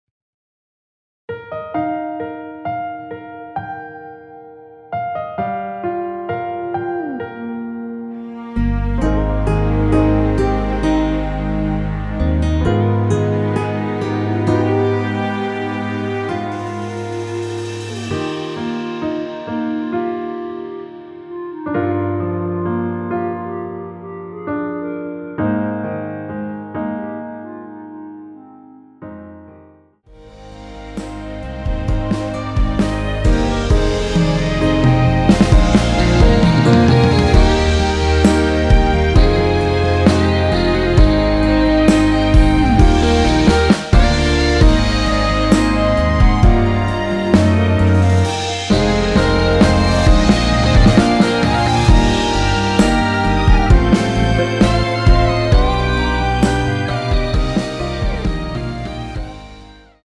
원키 멜로디 포함된 MR입니다.(미리듣기 확인)
Eb
앞부분30초, 뒷부분30초씩 편집해서 올려 드리고 있습니다.
중간에 음이 끈어지고 다시 나오는 이유는